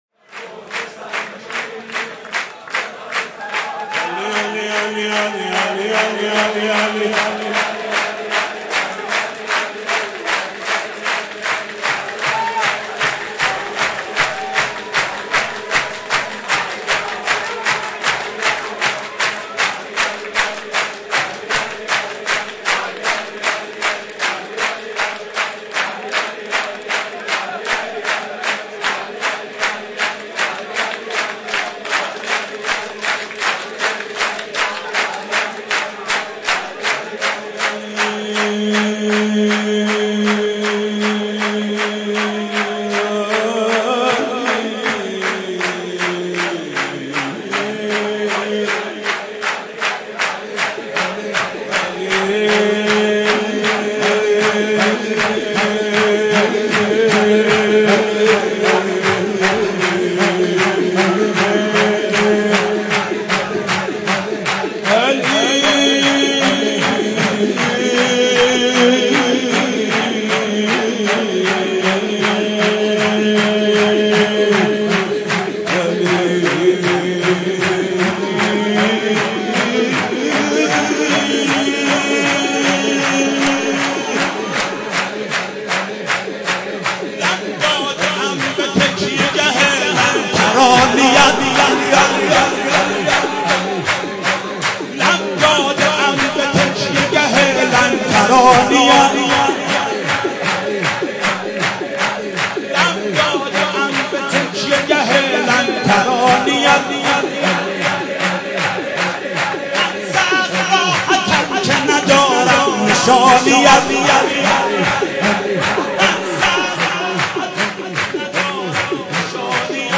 «میلاد امام رضا 1393» نغمه خوانی و مناجات